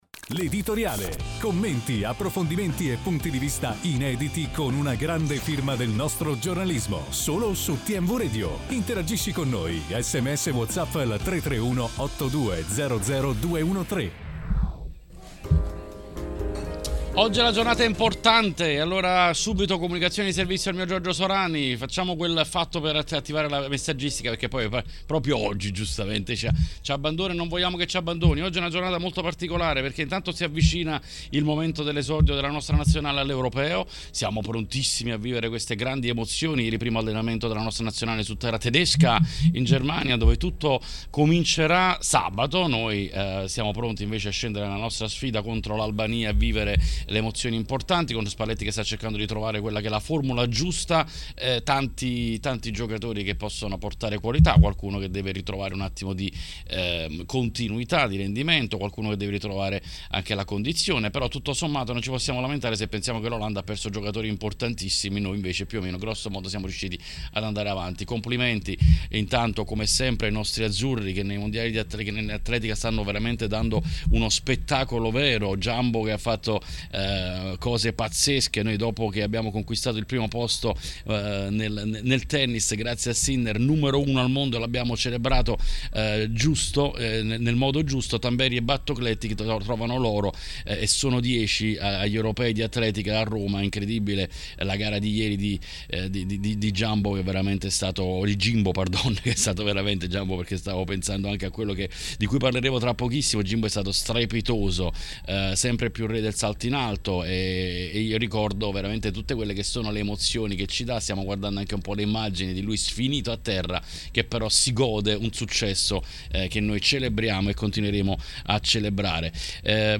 Durante l'appuntamento odierno con L'Editoriale è intervenuto sulle frequenze di TMW Radio Xavier Jacobelli. Queste le sue parole: